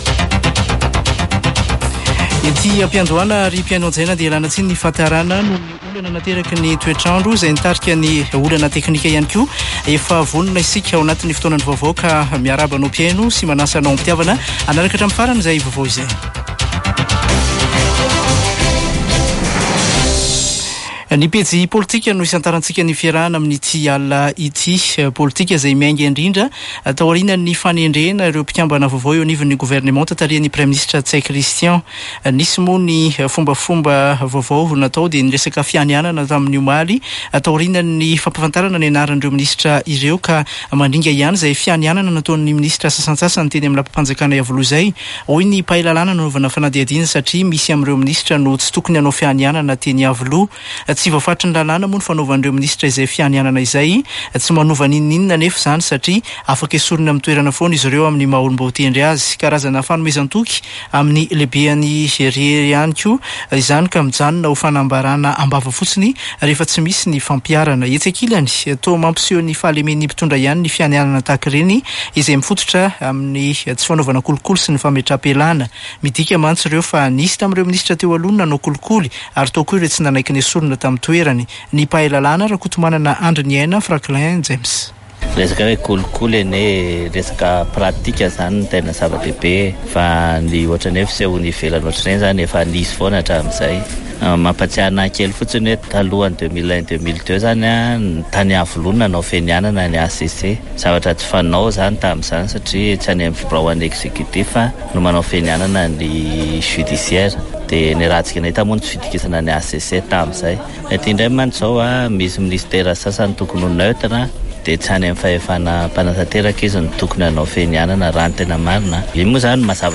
[Vaovao hariva] Alatsinainy 15 janoary 2024